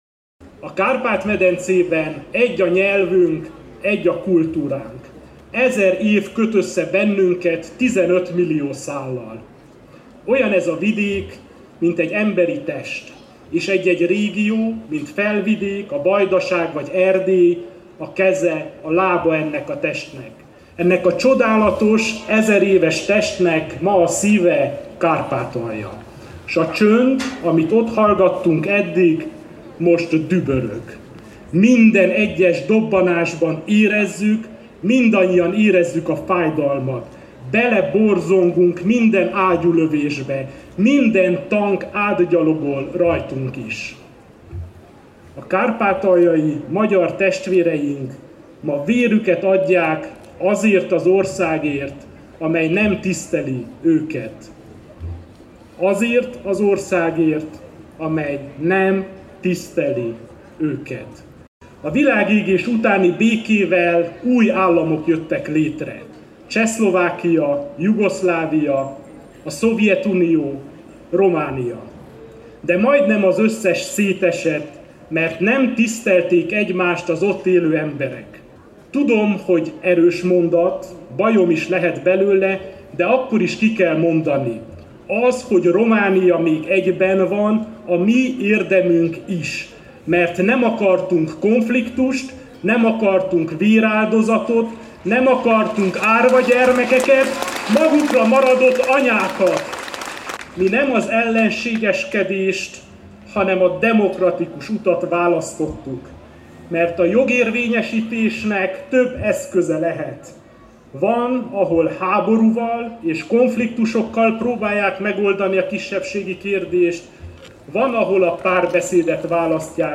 A főtéren Antal Árpád polgármester és Varga Judit magyarországi igazságügy miniszter mondott ünnepi beszédet majd Adorjáni Dezső Zoltán, az Evangélikus–Lutheránus Egyház püspöke áldotta meg az ünneplőket.
Az, hogy Románia még egyben van, a mi érdemünk is, mert nem akartunk konfliktust, fogalmazta meg március 15-i, ünnepi beszédében Antal Árpád. Sepsiszentgyörgy polgármestere felidézte, hogy a világégés utáni békével új államok jöttek létre: Csehszlovákia, Jugoszlávia, a Szovjetunió, Románia.